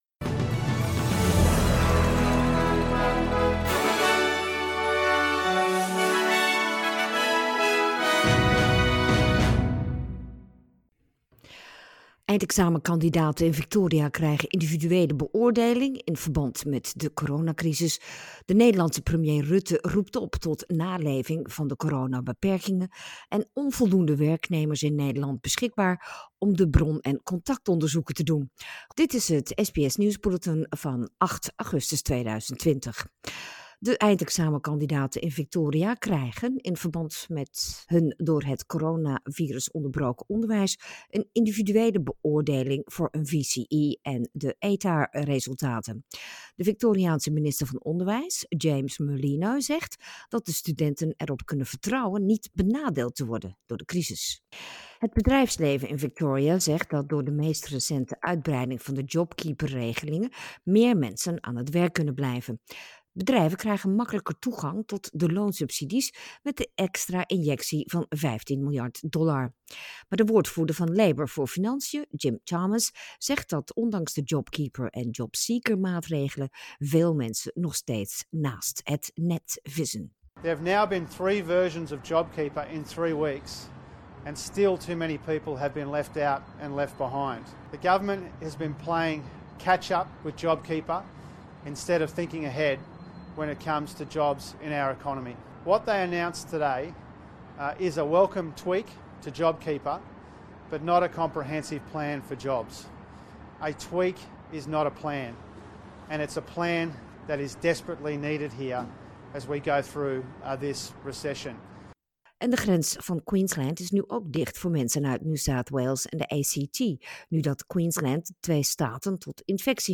Nederlands/Australisch SBS Dutch nieuws bulletin zaterdag 8 augustus
dutch_news_8_aug.mp3